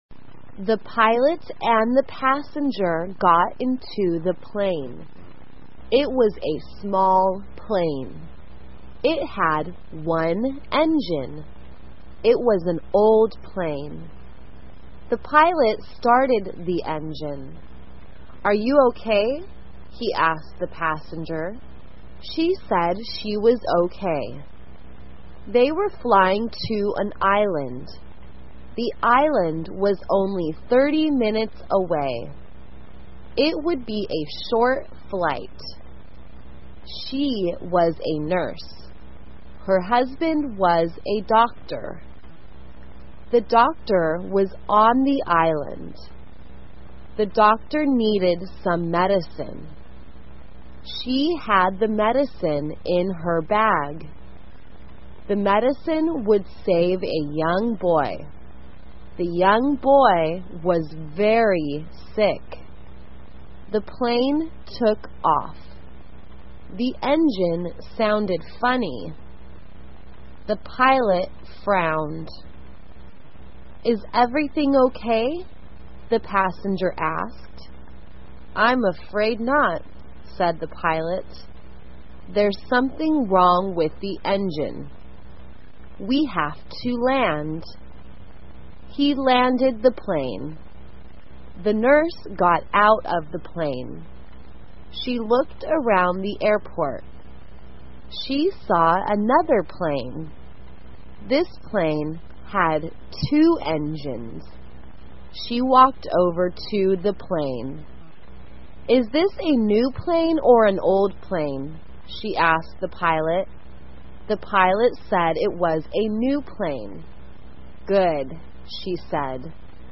慢速英语短文听力 短途飞机旅程 听力文件下载—在线英语听力室